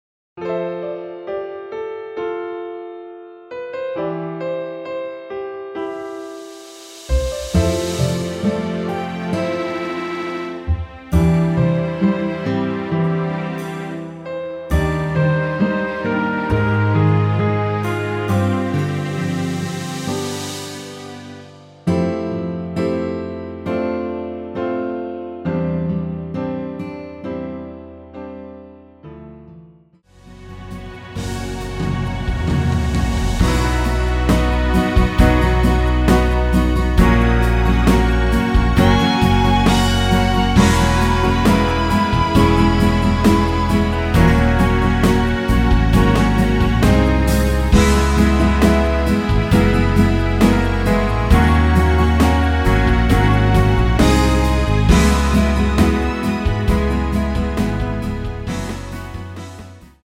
(-2) 내린 MR 입니다.
앞부분30초, 뒷부분30초씩 편집해서 올려 드리고 있습니다.
중간에 음이 끈어지고 다시 나오는 이유는
곡명 옆 (-1)은 반음 내림, (+1)은 반음 올림 입니다.